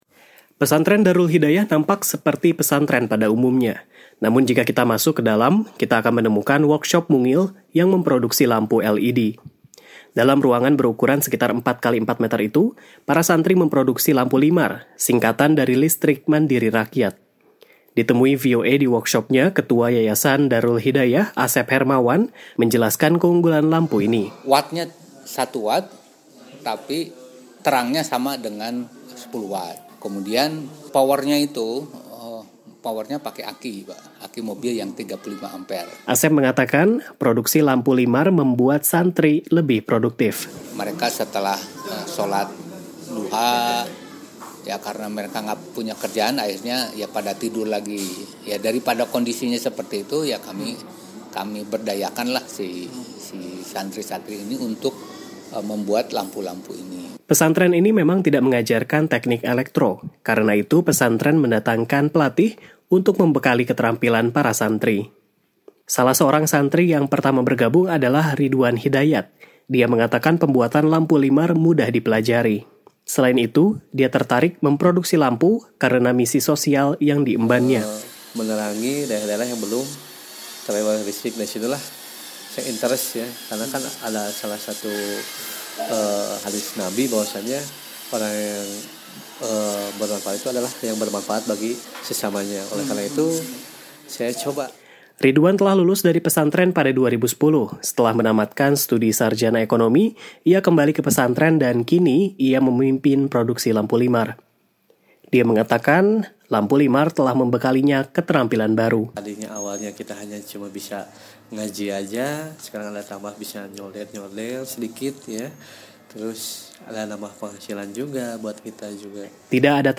Dalam bulan Ramadan ini, VOA Siaran Indonesia menghadirkan laporan khusus tentang kebangkitan pesantren-pesantren di Indonesia.